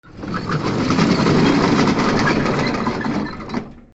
/ K｜フォーリー(開閉) / K05 ｜ドア(扉)
引き戸 檻
ピッチ下げ R26OM